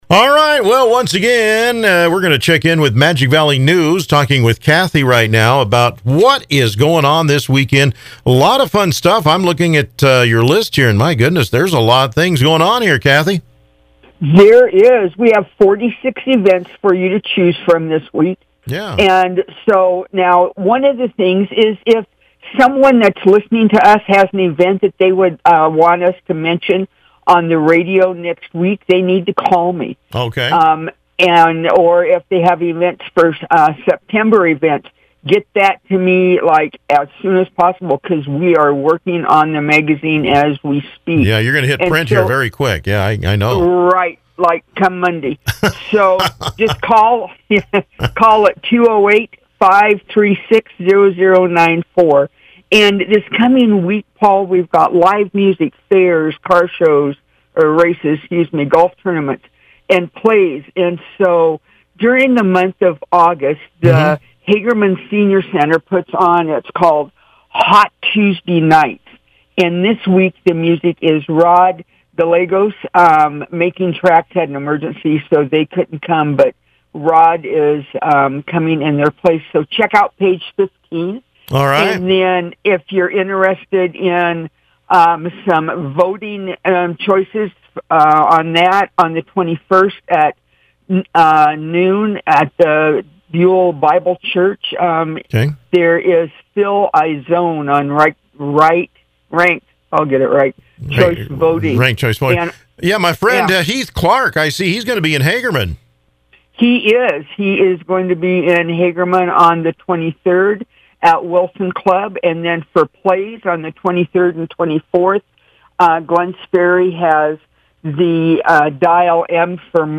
August 20 – BUCK FM chat August 21 – Kat Country chat